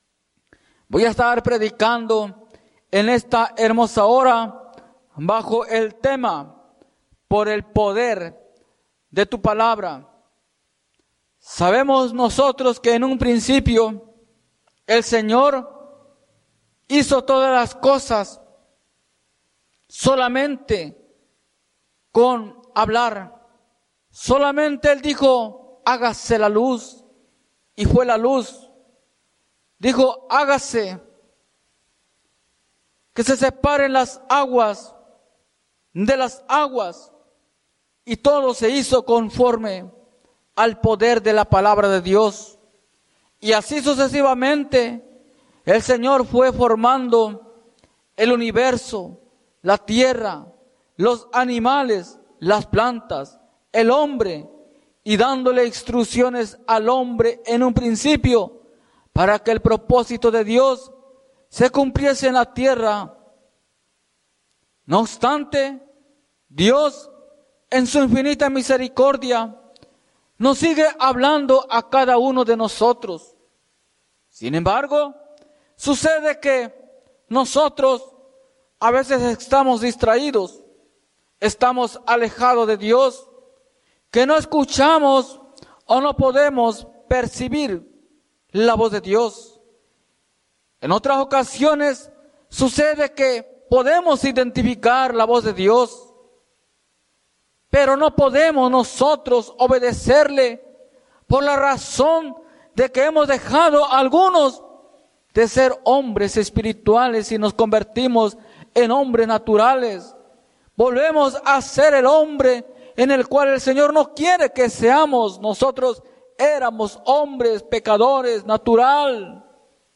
Iglesia Misión Evangélica
Predica